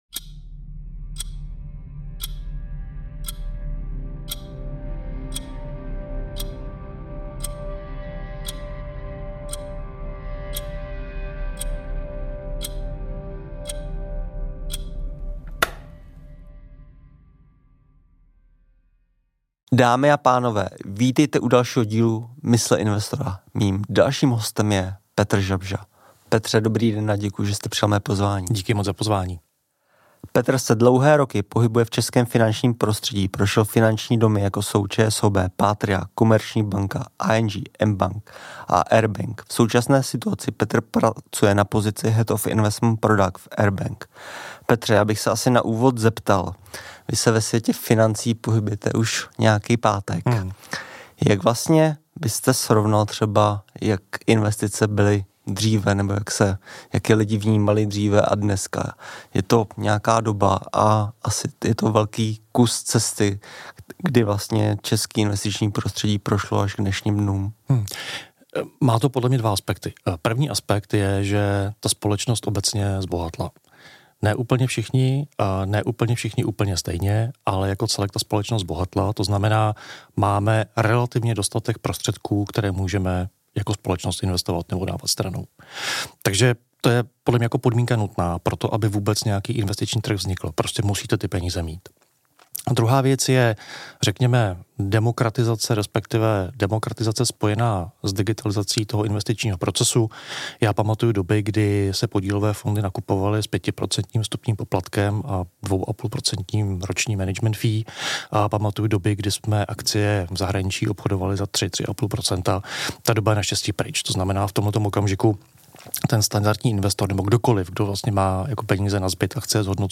Dále se zaměříme na investování z různých perspektiv, finanční gramotnost a vliv finančních influencerů. 🎙 V dnešním rozhovoru se budeme bavit o těchto tématech: